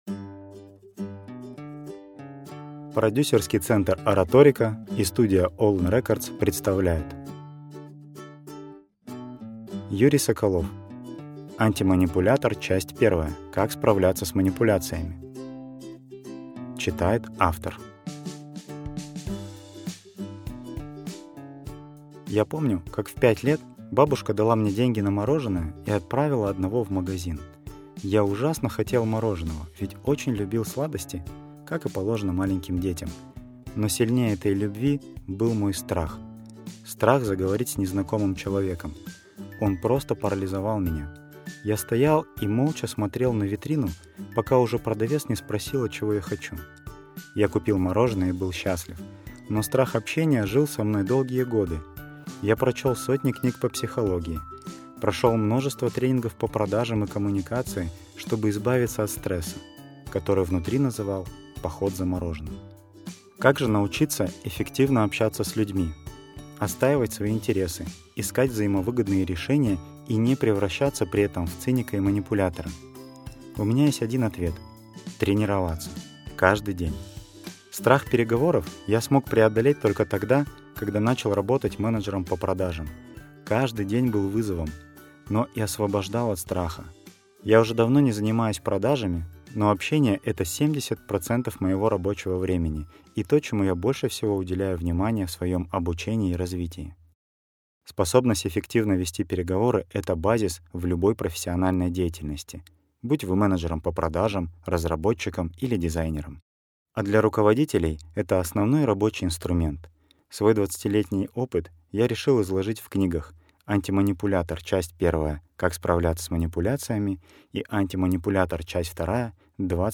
Аудиокнига Антиманипулятор. Часть 1: Как справляться с манипуляциями | Библиотека аудиокниг